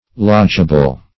Search Result for " lodgeable" : The Collaborative International Dictionary of English v.0.48: Lodgeable \Lodge"a*ble\, a. [Cf. F. logeable.] 1. That may be or can be lodged; as, so many persons are not lodgeable in this village.